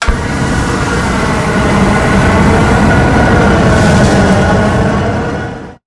flamethrower_shot_01.wav